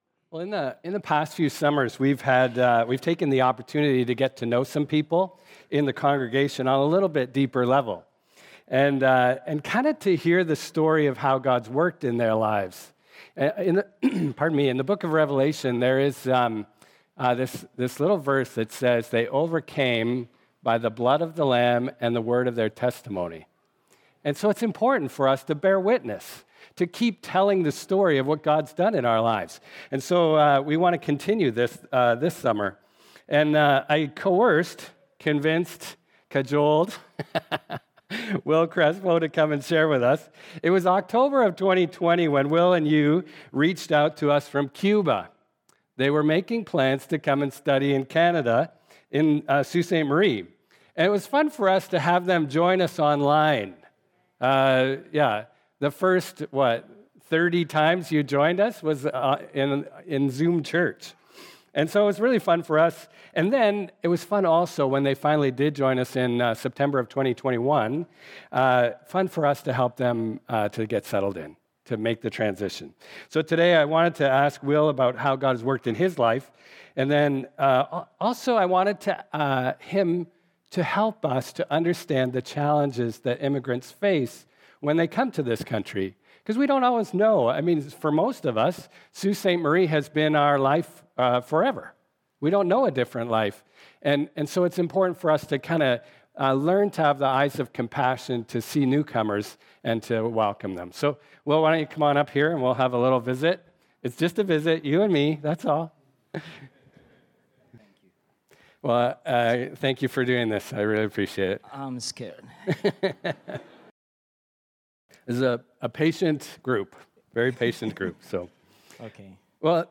Sermons | Bethany Baptist Church